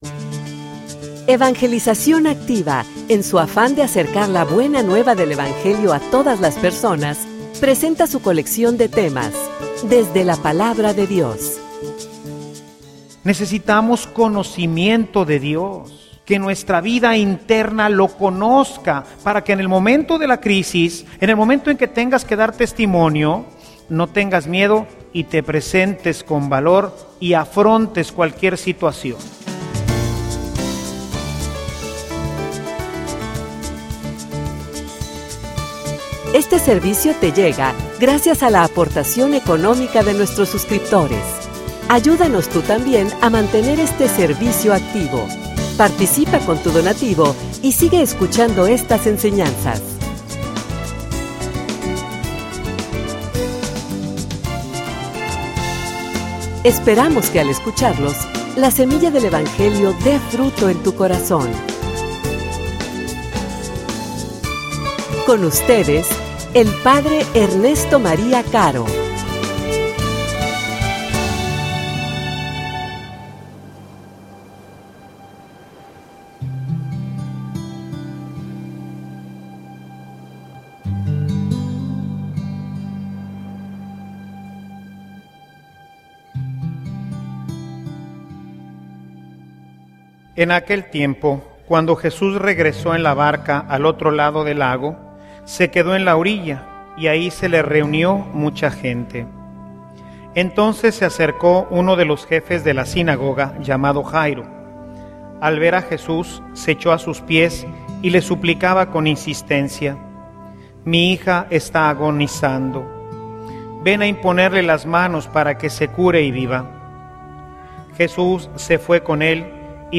homilia_Conocimiento_de_Dios.mp3